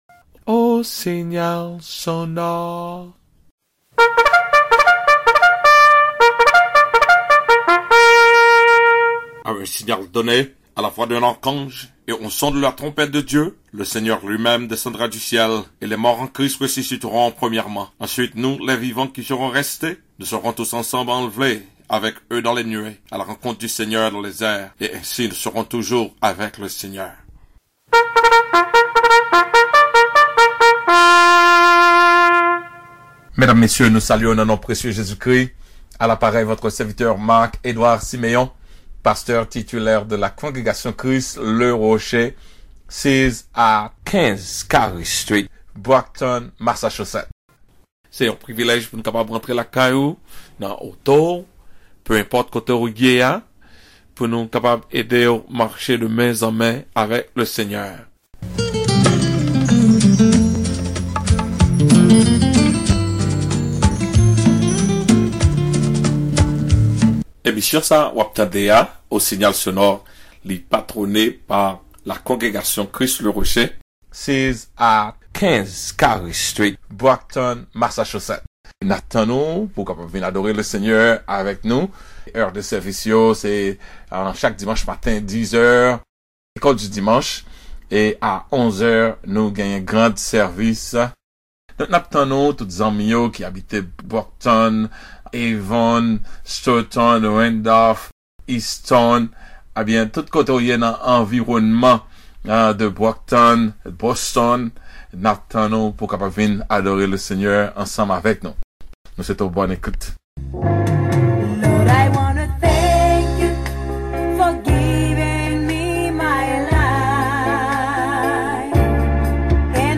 PEZE (PLAY) POU’W KA TANDE MESAJ LA AN KREYOL